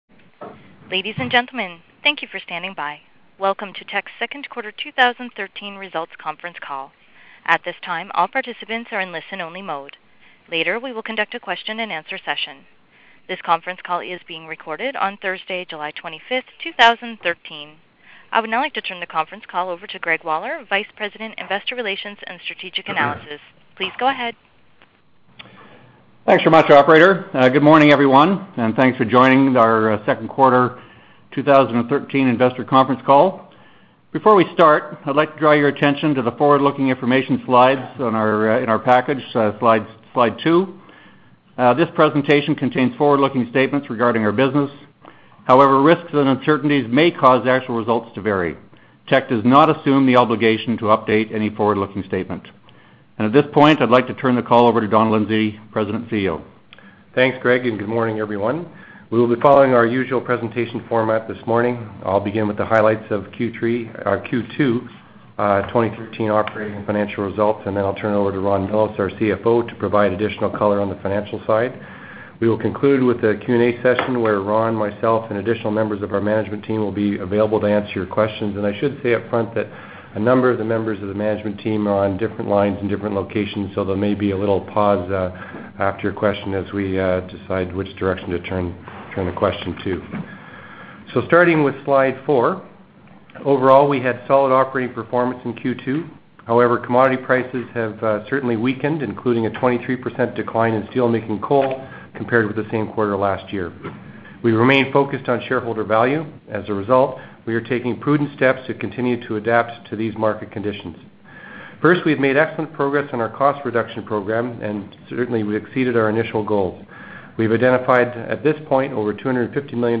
Q2-2013-Conference-Call-Audio.mp3